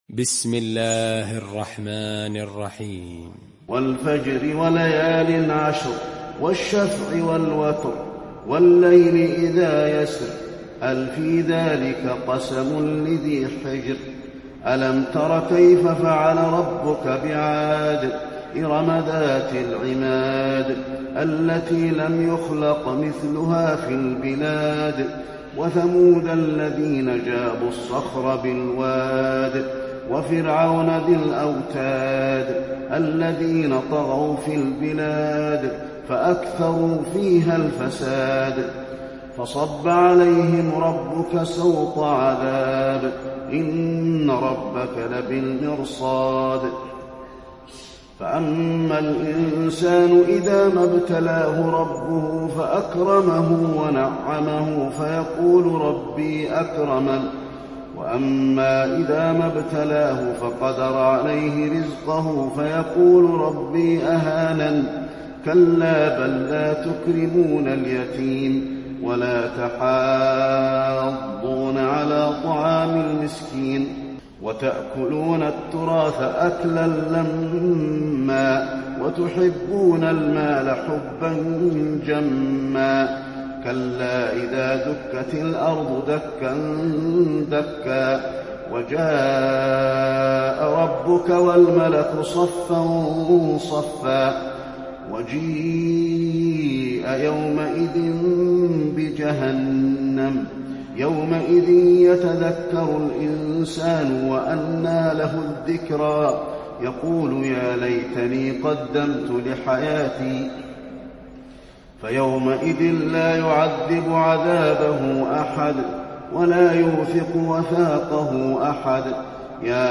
المكان: المسجد النبوي الفجر